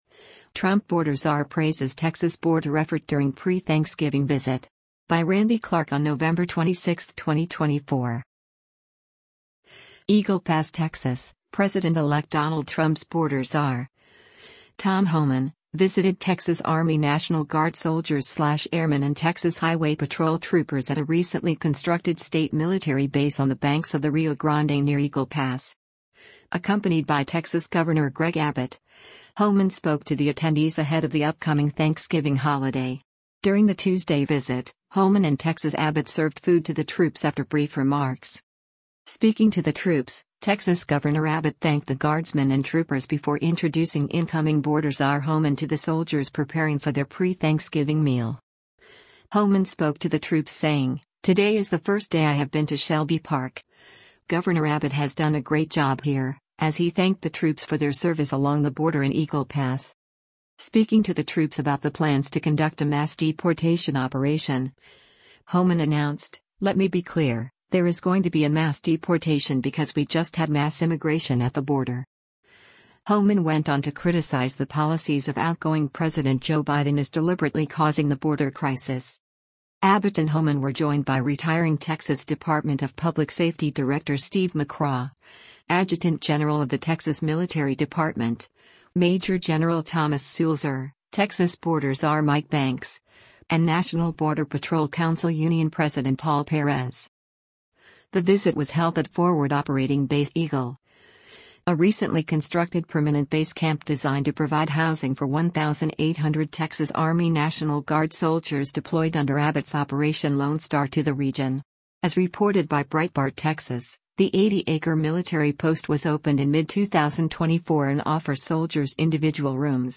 EAGLE PASS, Texas — President-Elect Donald Trump’s Border Czar, Tom Homan, visited Texas Army National Guard soldiers/airmen and Texas Highway Patrol troopers at a recently constructed state military base on the banks of the Rio Grande near Eagle Pass.
Speaking to the troops, Texas Governor Abbott thanked the guardsmen and troopers before introducing incoming Border Czar Homan to the soldiers preparing for their pre-Thanksgiving meal.